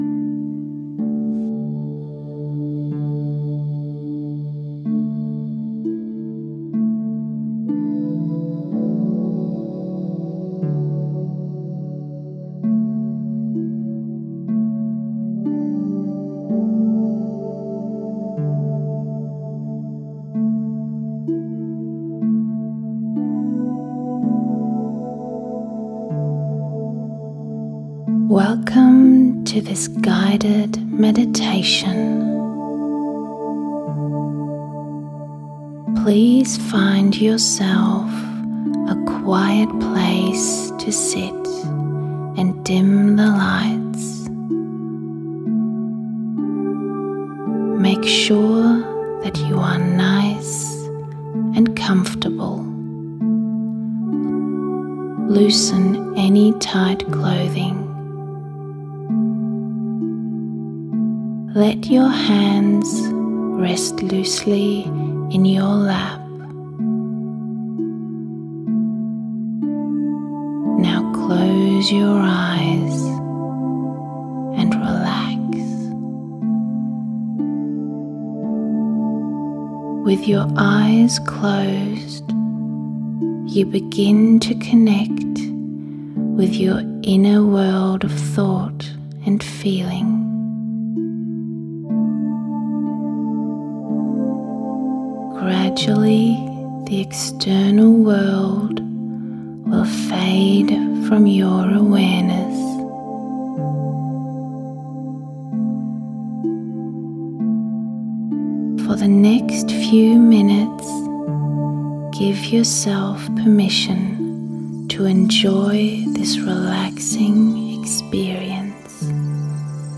EnchantedMind-Meditation.mp3